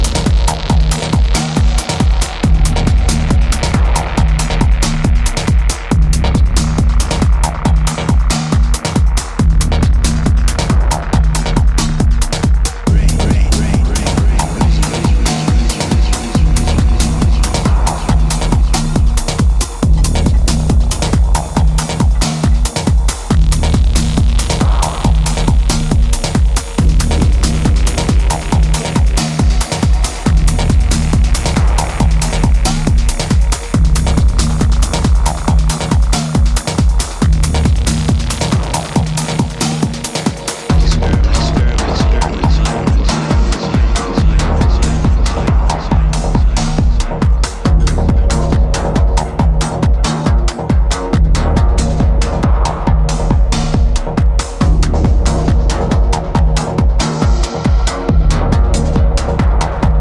ダブテックを軸により間口の広いスタイルのテクノを表現した充実作です。